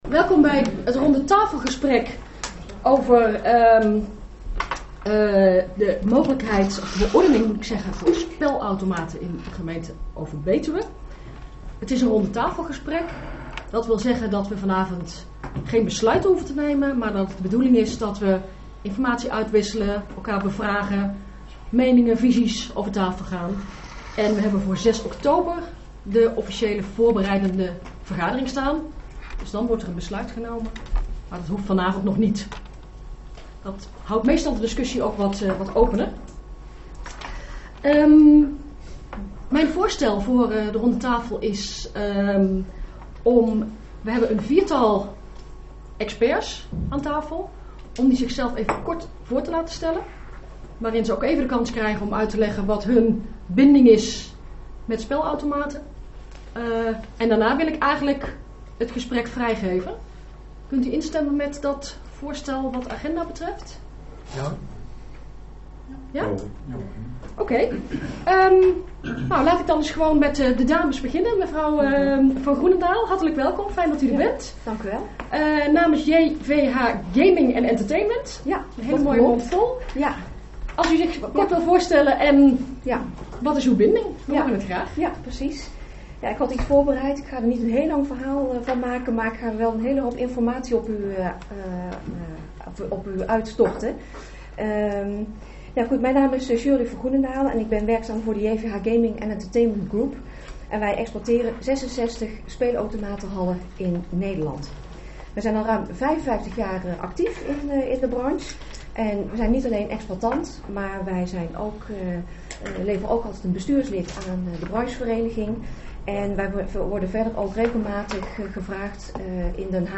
Locatie S02, gemeentehuis Elst Voorzitter mevr.